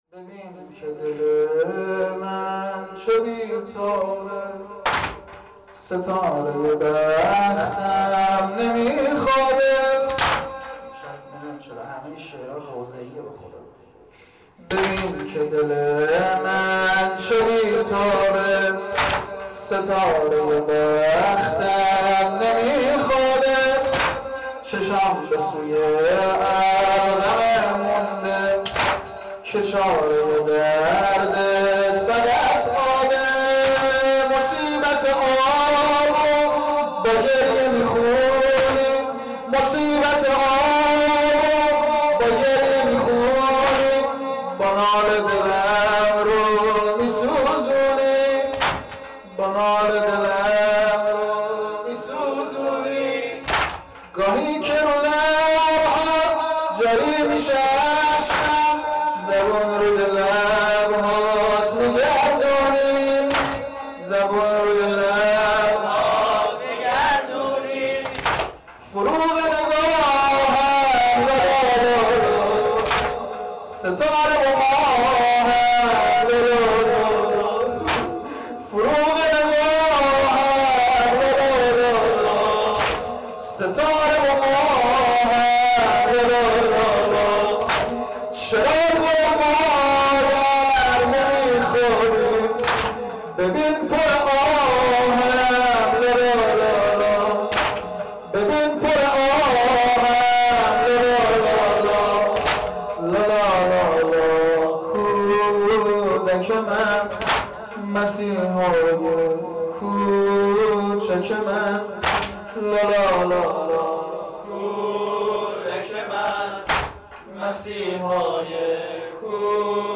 • مراسم سینه زنی شب هفتم محرم هیئت روضه الحسین